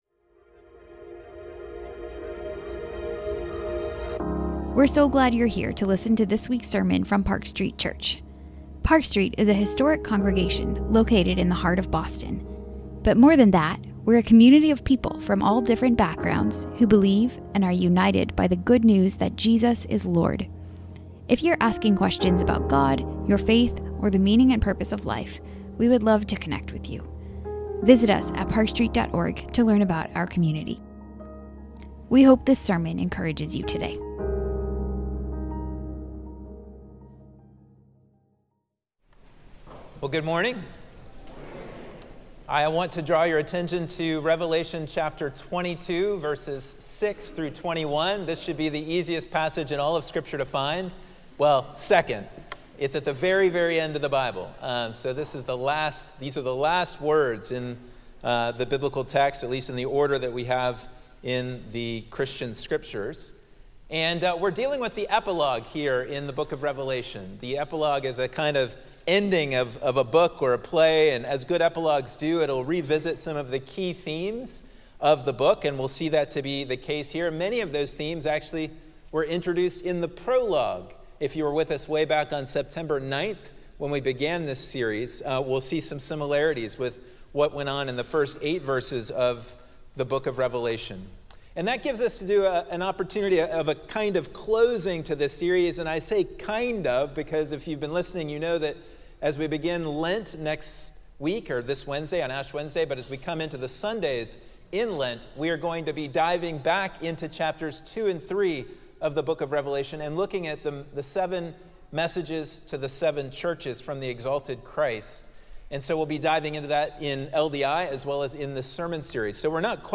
This sermon explores how Revelation calls us to urgent, faithful, worshipful, Christ-centered, and hope-filled witness amidst conflict as we wait for Christ to return.